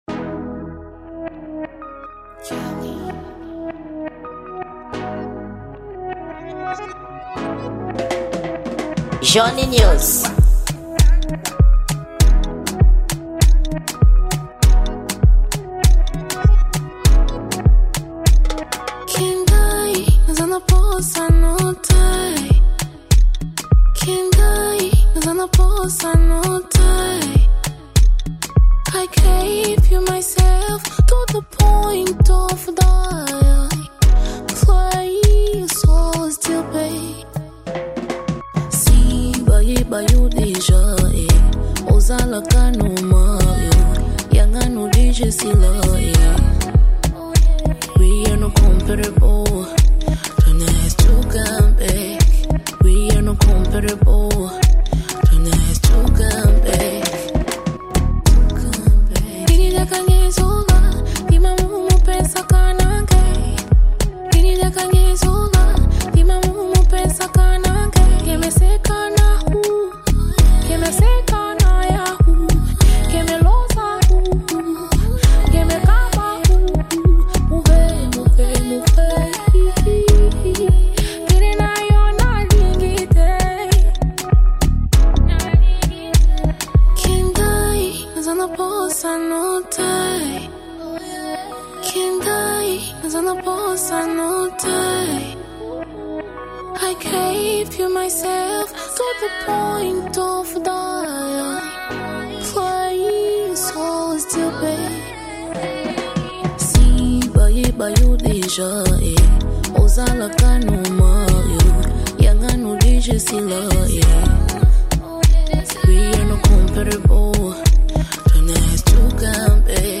Gênero: Afro Pop